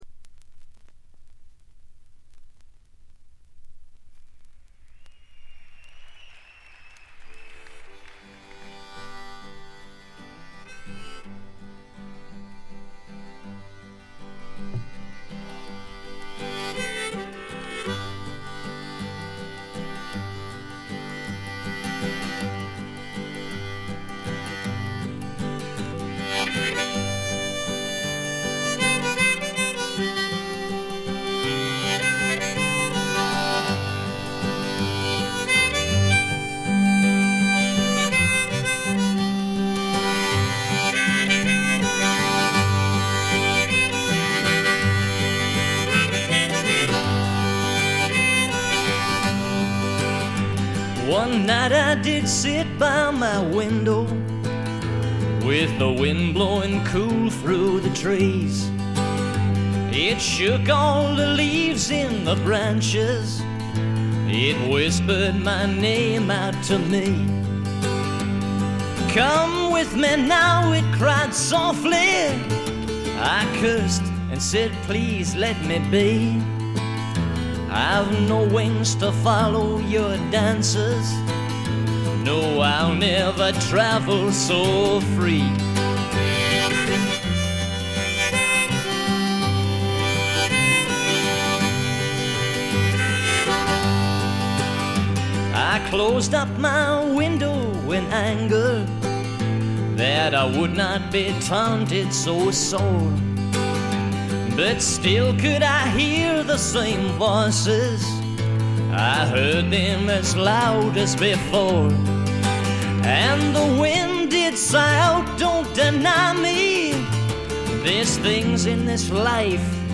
気付いたのはこれぐらいで、ほとんどノイズ感無し。
試聴曲は現品からの取り込み音源です。